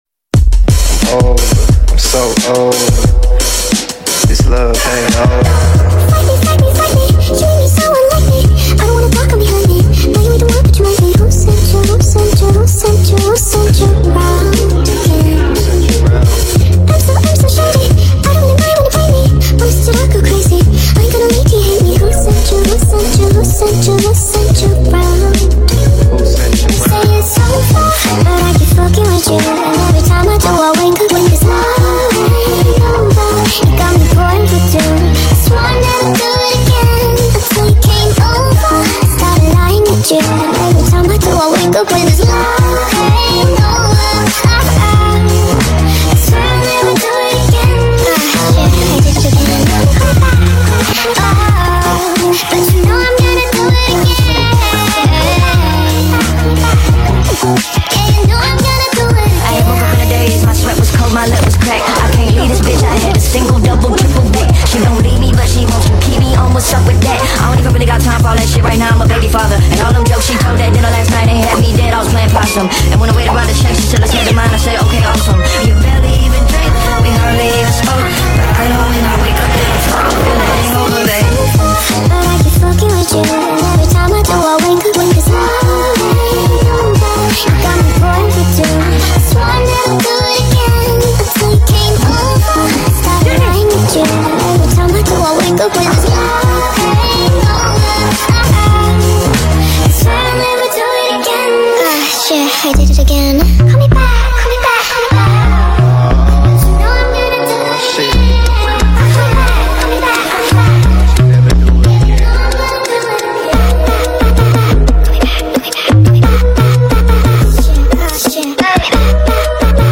SPEED UP FULL SONG